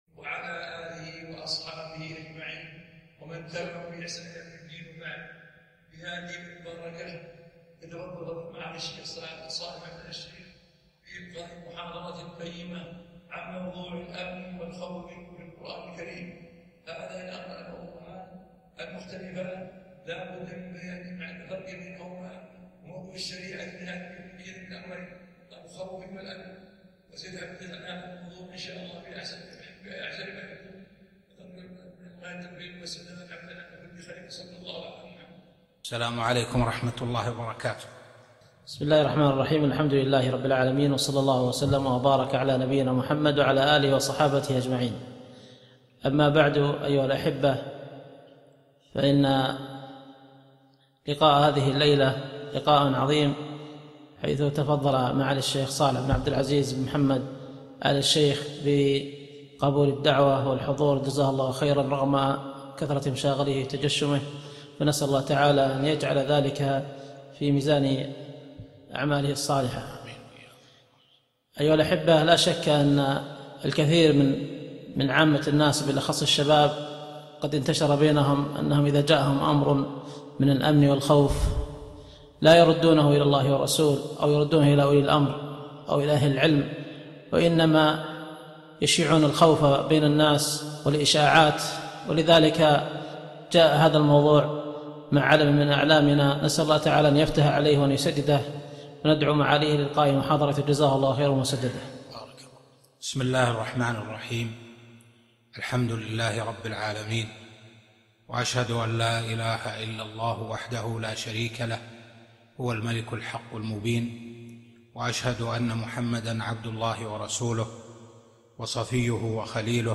محاضرة - الأمن والخوف في القرآن الكريم